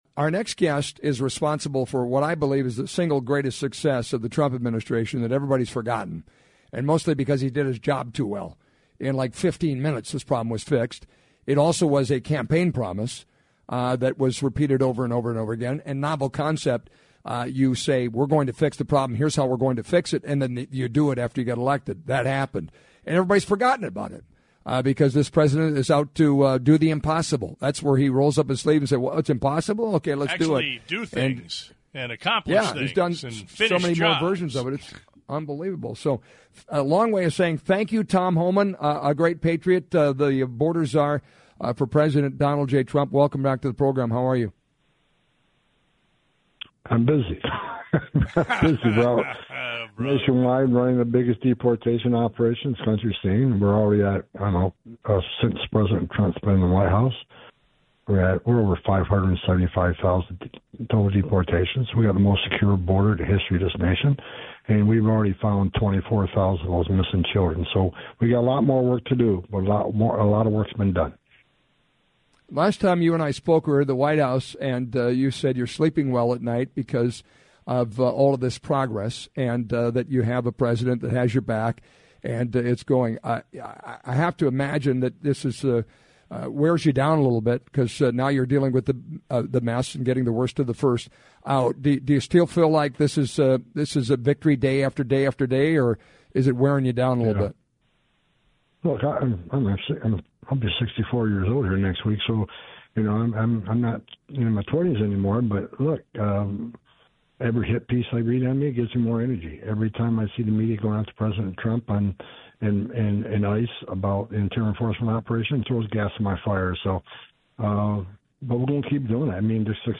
Previously serving as Director of Immigration and Customs Enforcement in the first Trump Administration, he now serves as Trump’s border czar.